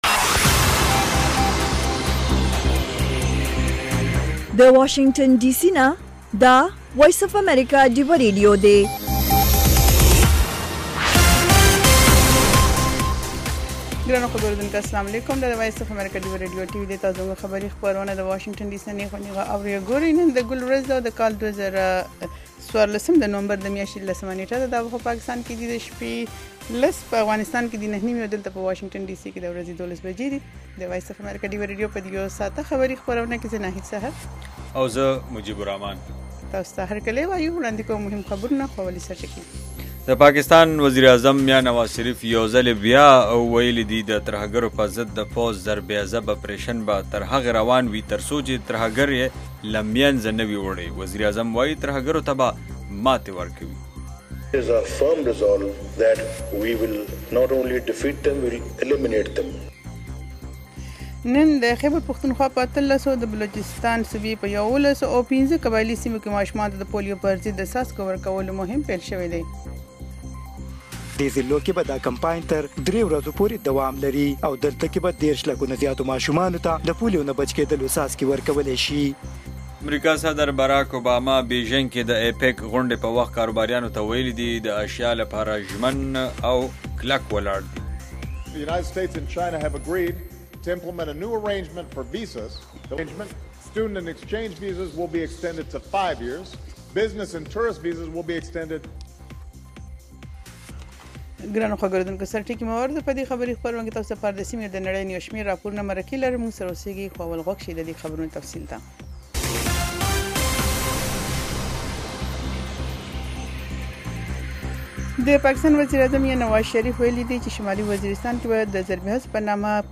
خبرونه
د وی او اې ډيوه راډيو ماښامنۍ خبرونه چالان کړئ اؤ د ورځې د مهمو تازه خبرونو سرليکونه واورئ. په دغه خبرونو کې د نړيوالو، سيمه ايزو اؤمقامى خبرونو هغه مهم اړخونه چې سيمې اؤ پښتنې ټولنې پورې اړه لري شامل دي. دخبرونو په دې جامع وخت کې دسياسياتو، اقتصاد، هنر ، ټنګ ټکور، روغتيا، موسم اؤ لوبو په حقله ځانګړې ورځنۍ فيچرې شاملې دي.